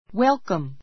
welcome 小 A1 wélkəm ウェ る カ ム 意味map 間投詞 いらっしゃい!